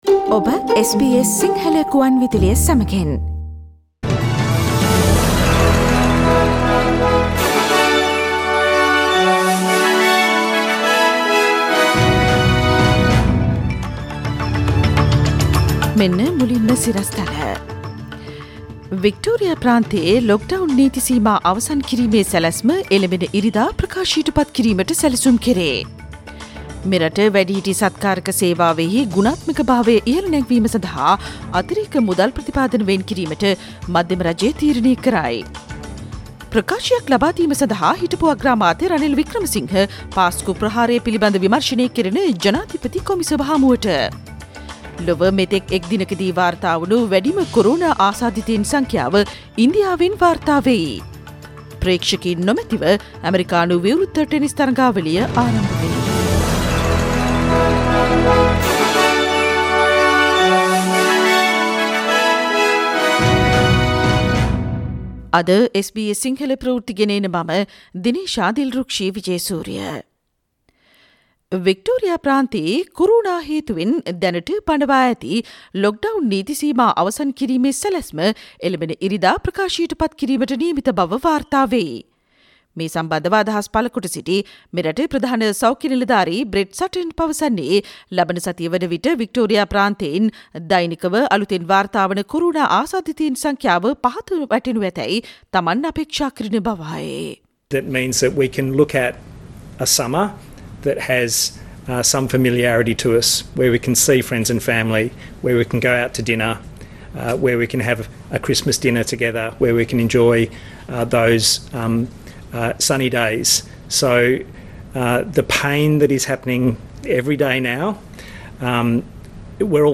Daily News bulletin of SBS Sinhala Radio:Tuesday 1 September 2020
Today’s news bulletin of SBS Sinhala radio – Tuesday 1 September 2020.